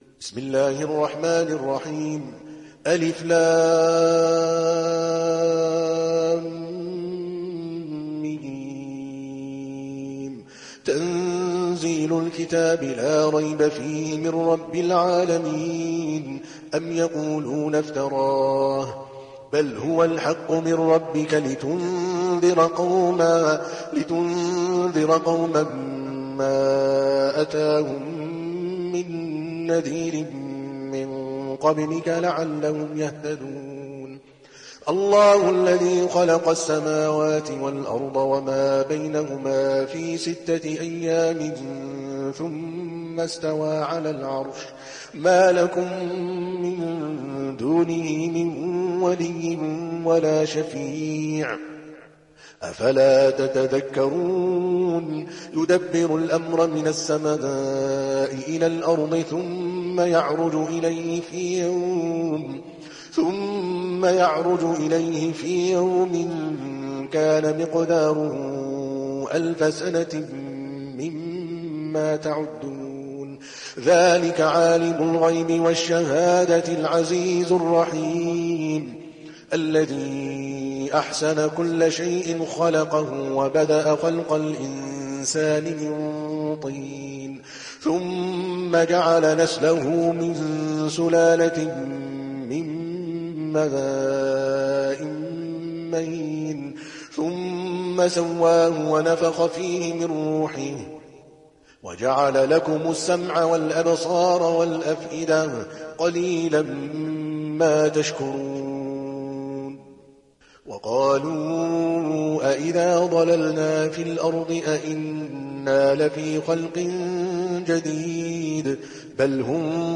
تحميل سورة السجدة mp3 بصوت عادل الكلباني برواية حفص عن عاصم, تحميل استماع القرآن الكريم على الجوال mp3 كاملا بروابط مباشرة وسريعة